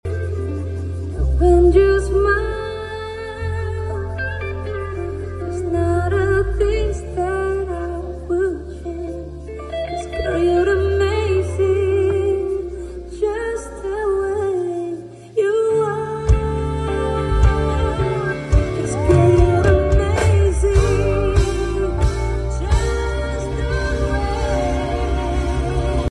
CVR line array and subwoofer sound effects free download
CVR line array and subwoofer 16 tops+4 sub